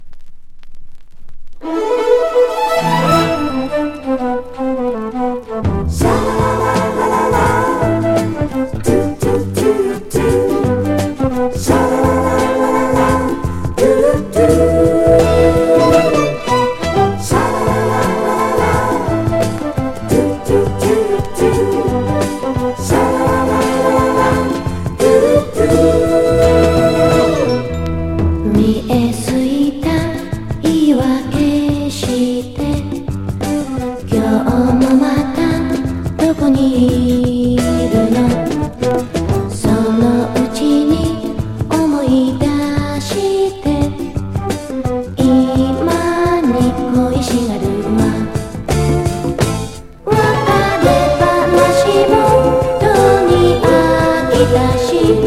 シャバダバ・サイケ・ムード歌謡